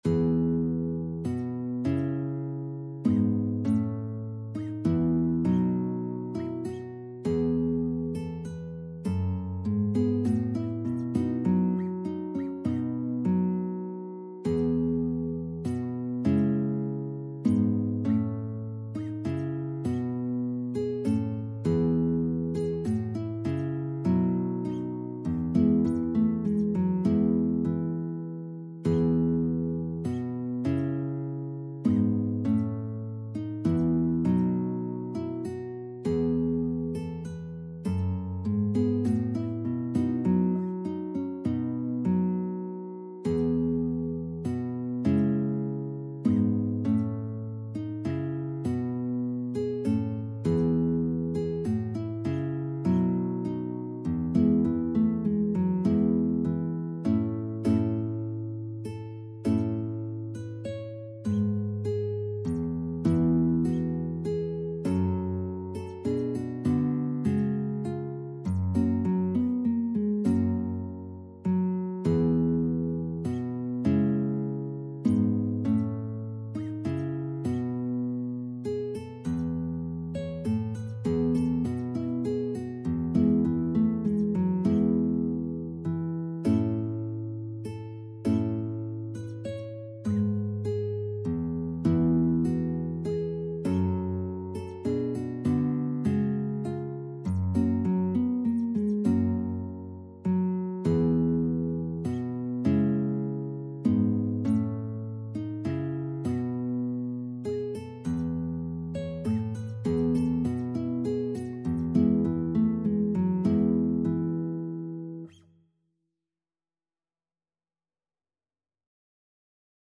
Classical Dowland, John Frog Galliard Guitar version
E major (Sounding Pitch) (View more E major Music for Guitar )
3/4 (View more 3/4 Music)
E3-Db6
Guitar  (View more Intermediate Guitar Music)
Classical (View more Classical Guitar Music)